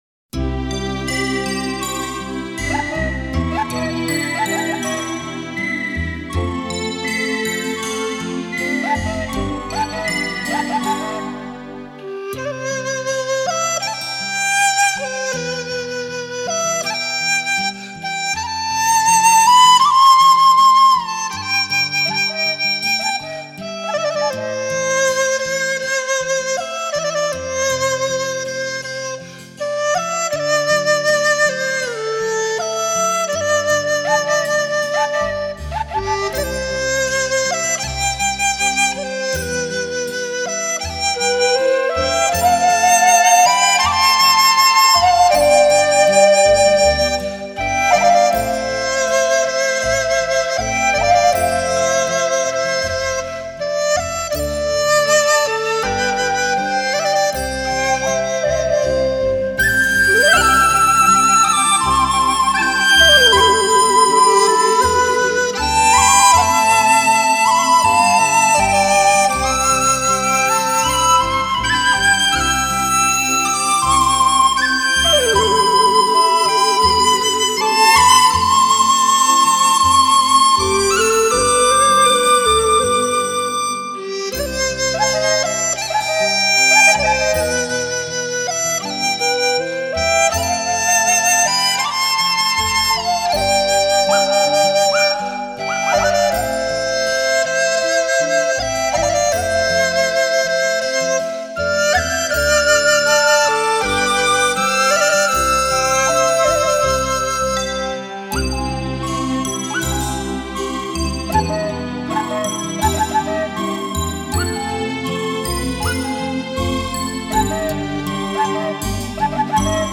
笛乐精品
以曲笛、梆笛、新笛、口笛、低音笛、木笛为主，辅以领奏、轮奏、二重奏、三重奏加之背景技巧融为一体的全新演绎手法。